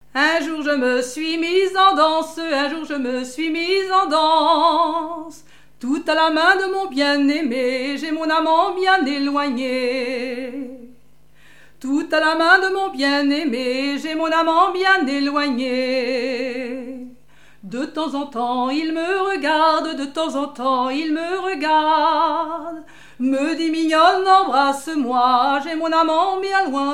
ronde : rond de l'Île d'Yeu
Répertoire de chansons
Pièce musicale inédite